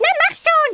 Amiga 8-bit Sampled Voice
1 channel
Hello.mp3